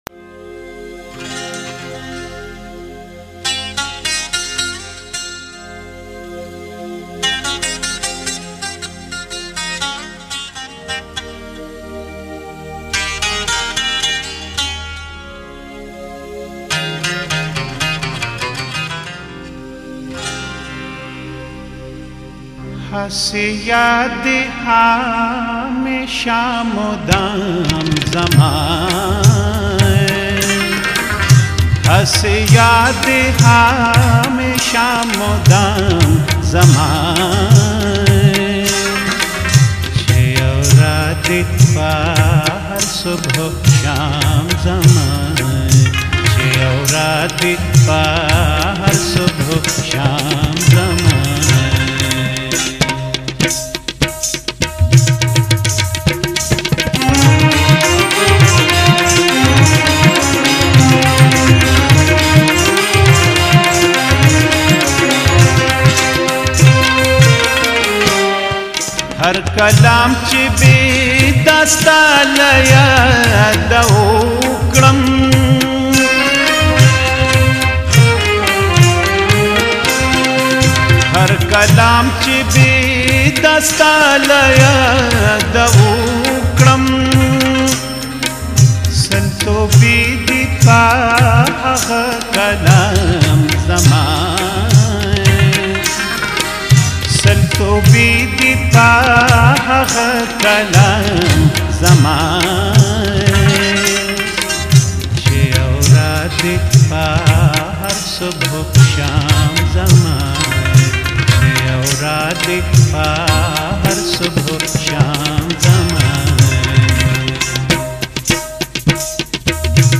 ټنګ ټکور